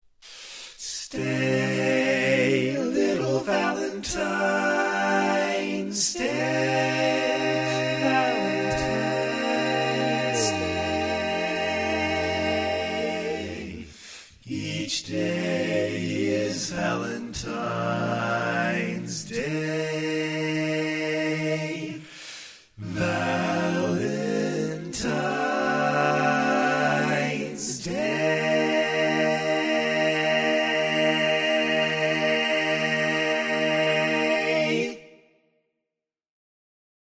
Key written in: B Minor
How many parts: 4
Type: Barbershop
All Parts mix: